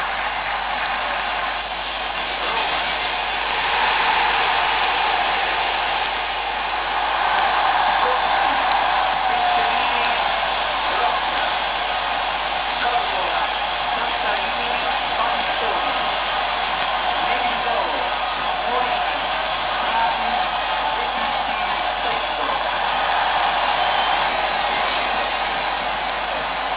Stadio Olimpico
formazioneromaderby75.wav